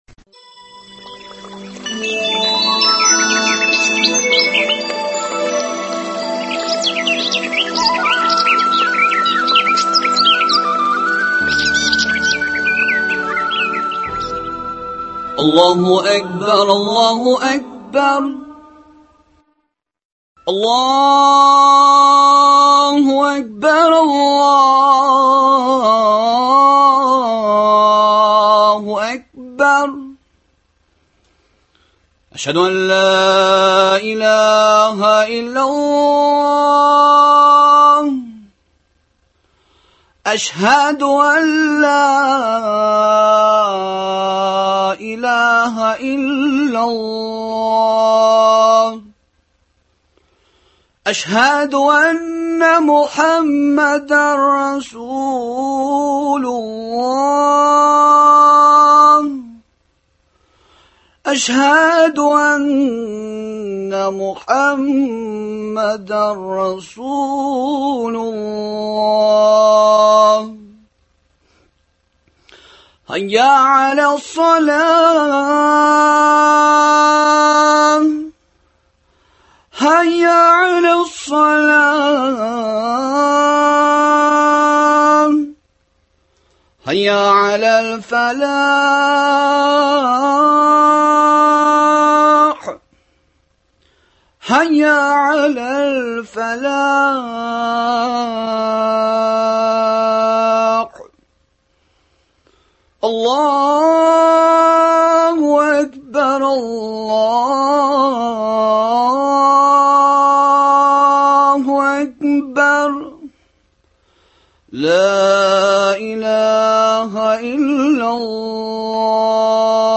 әңгәмәләр циклы